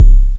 INSKICK19 -R.wav